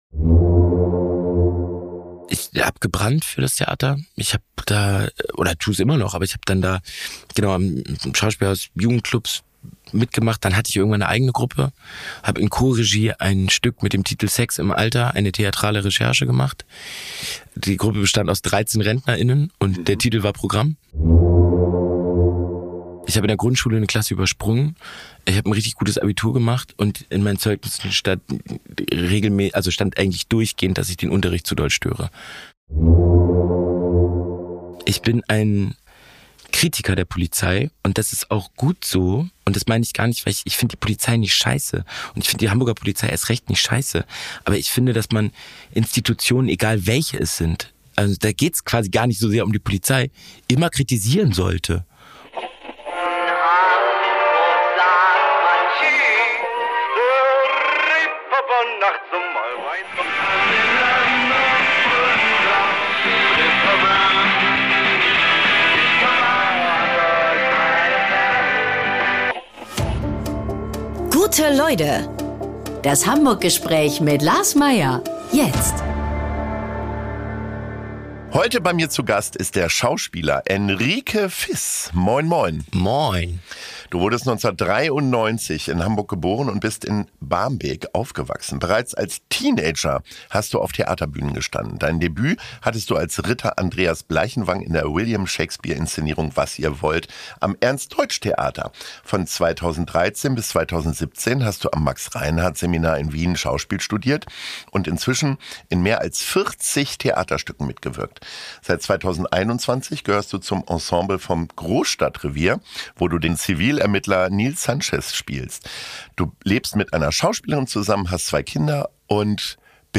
Radiosendung